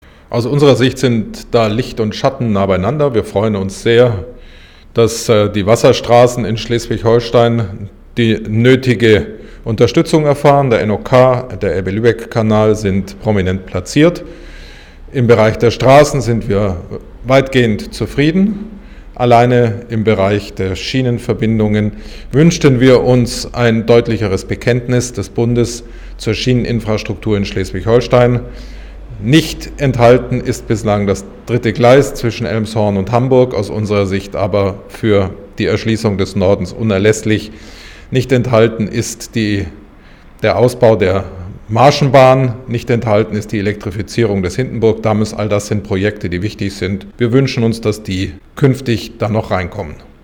Verkehrs-Staatssekretär Frank Nägele sagte dazu.